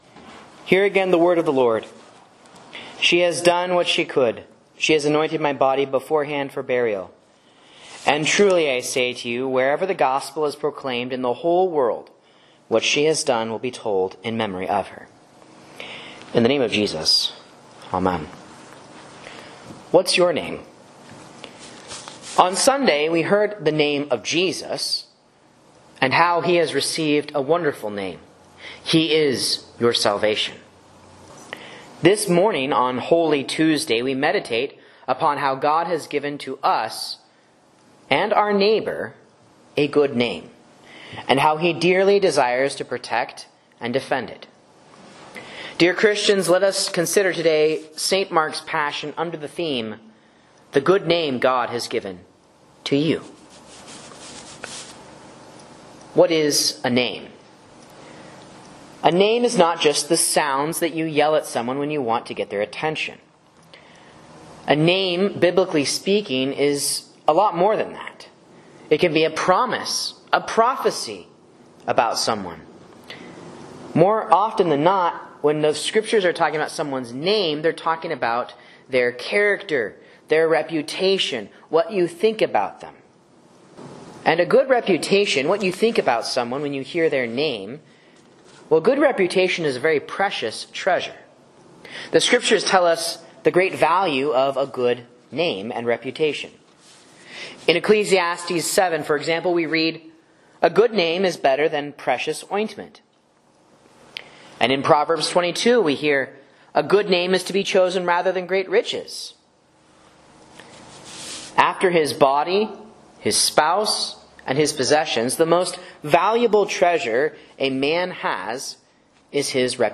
Sermon and Bible Class Audio from Faith Lutheran Church, Rogue River, OR
A Sermon on St. Mark's Passion & the 8th Commandment for Holy Tuesday